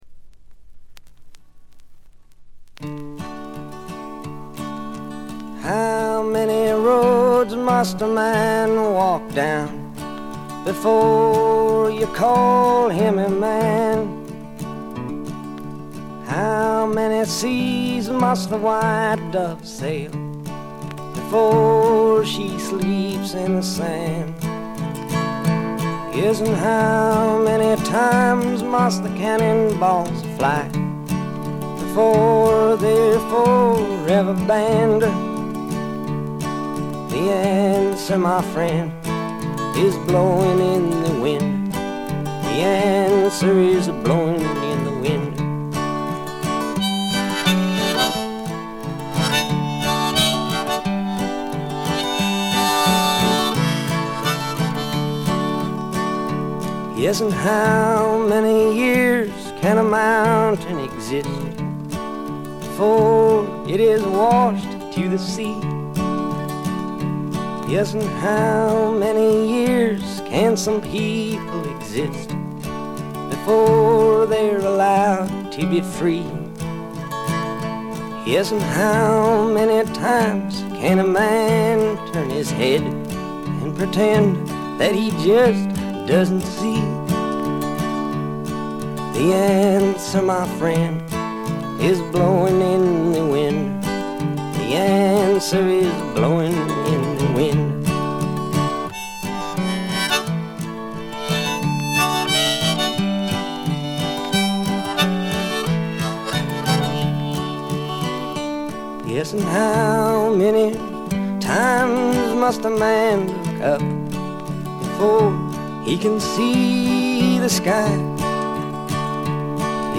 全体にバックグラウンドノイズ、チリプチ多め大きめですが音は見た目よりずっといい感じです。
試聴曲は現品からの取り込み音源です。